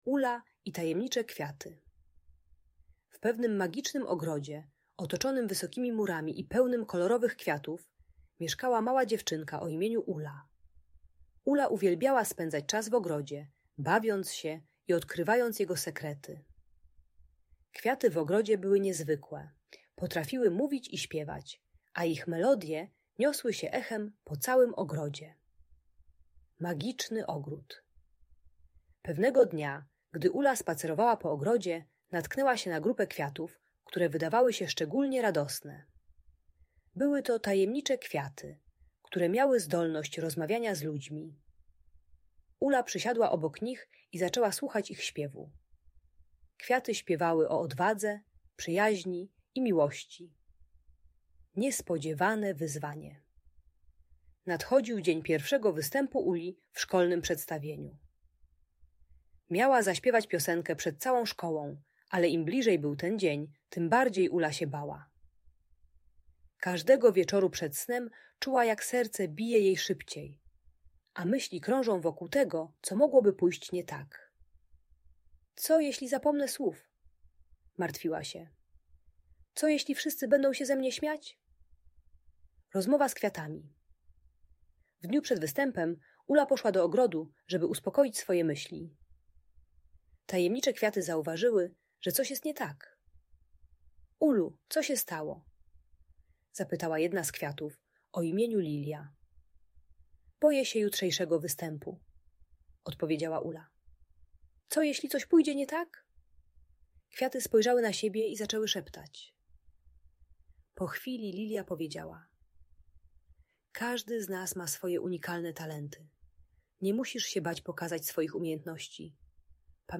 Ula i Tajemnicze Kwiaty - Audiobajka